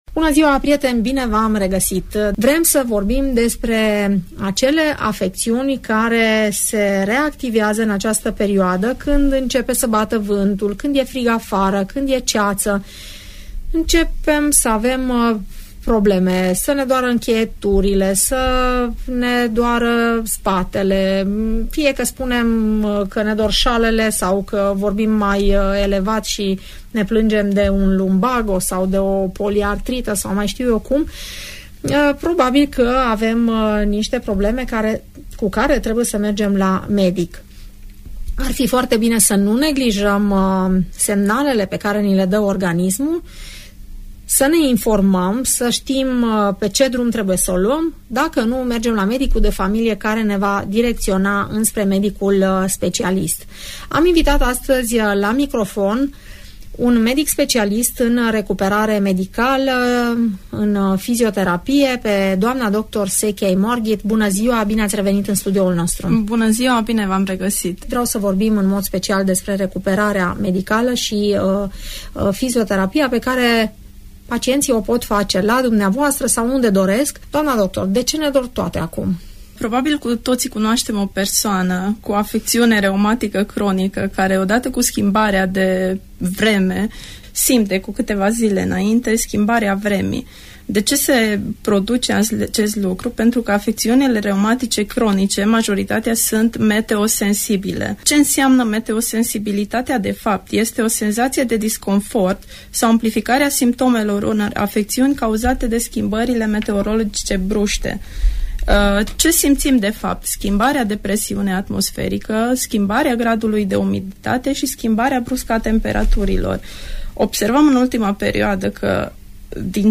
urmărind emisiunea „Părerea ta” la Radio Tg Mureș